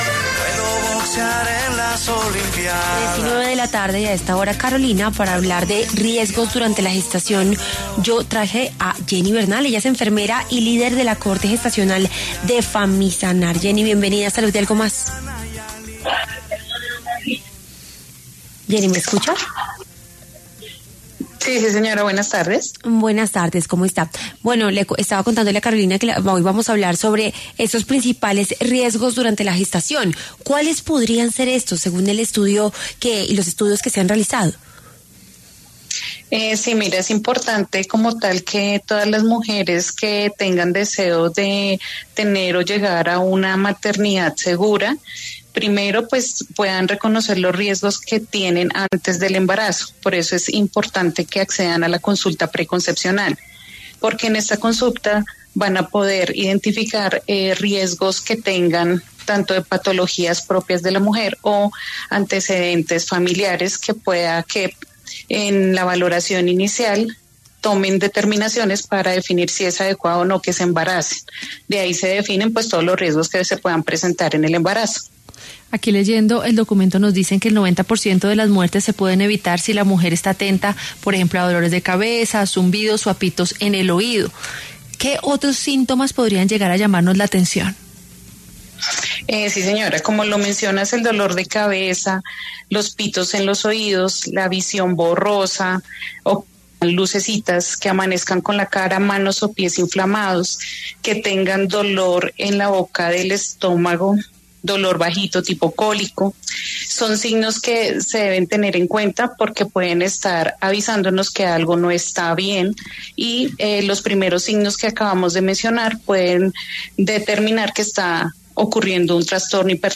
enfermera y experta en el proceso de gestación, habló sobre los principales riesgos durante el embarazo en los micrófonos de Salud y algo más.